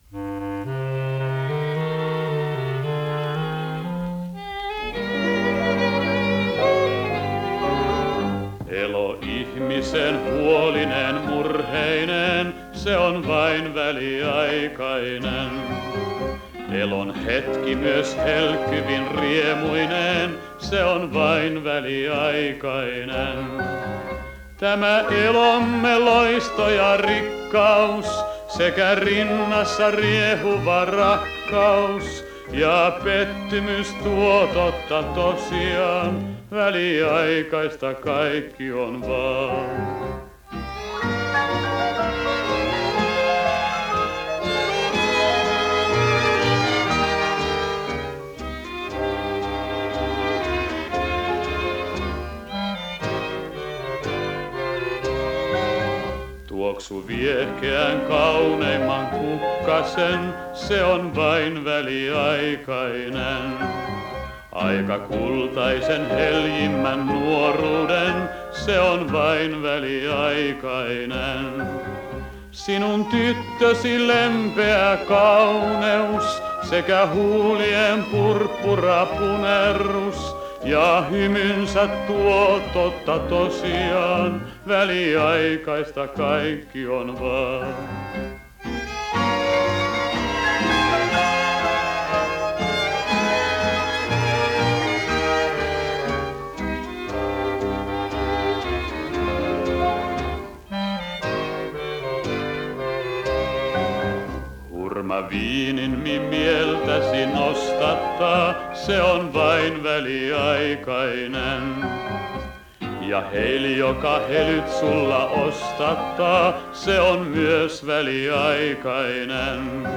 Panssarilaiva Ilmarisen tarina on saatavissa kuunnelmana . Panssarilaiva oli merisodan kummajainen.